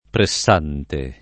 pressante
pressante [ pre SS# nte ] agg.